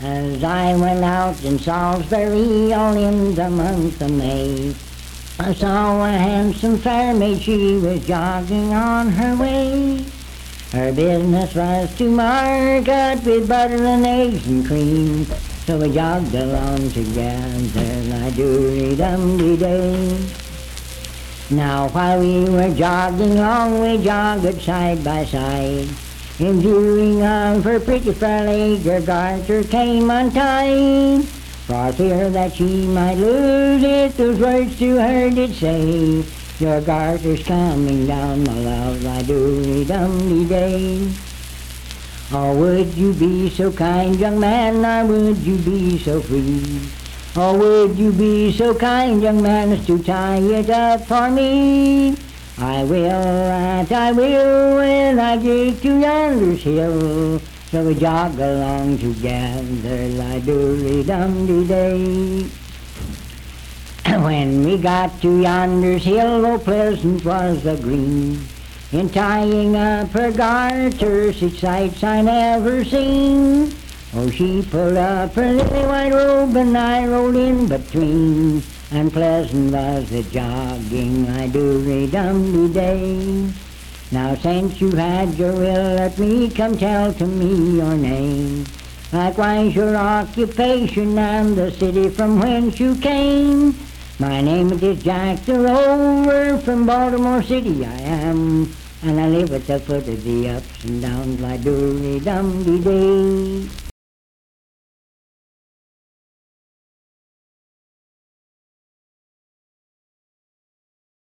Unaccompanied vocal music
Performed in Sandyville, Jackson County, WV.
Bawdy Songs
Voice (sung)